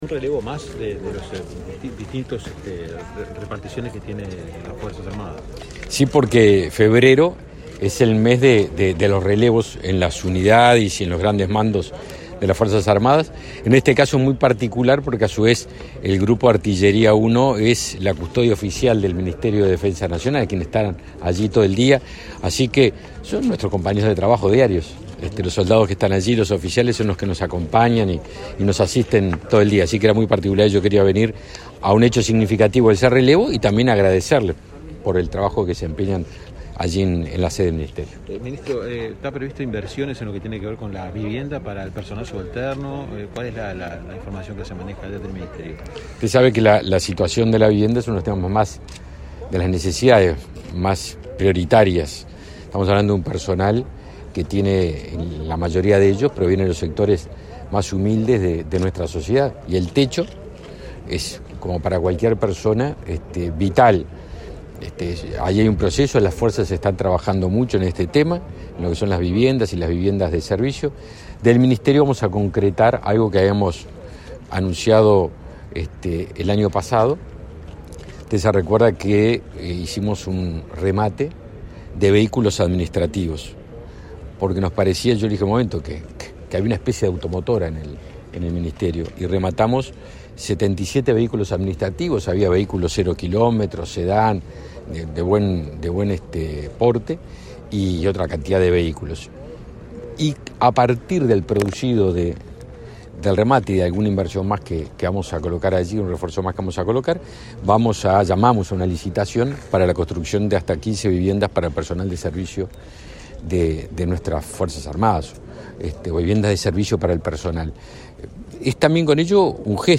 Declaraciones a la prensa del ministro Javier García
El titular de Defensa Nacional, Javier García, participó este martes 8 en la ceremonia de toma de posesión del nuevo jefe del Grupo de Artillería N.º